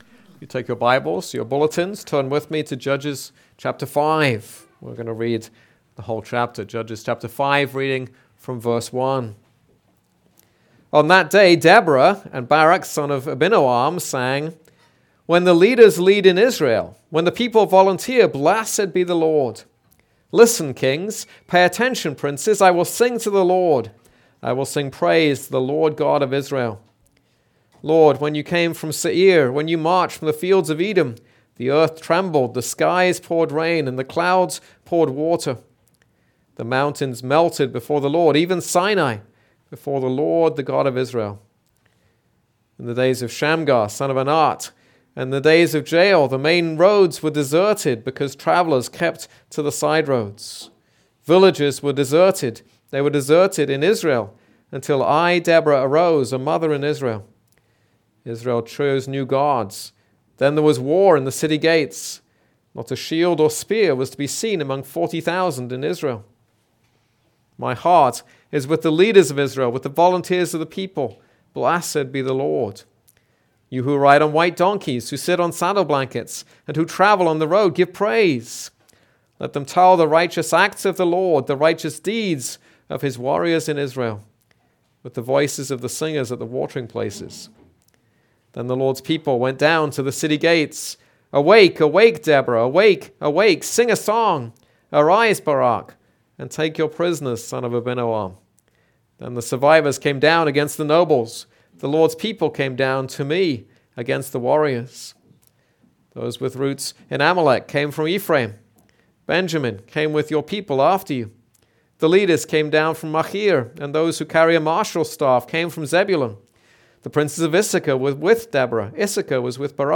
This is a sermon on Judges 5.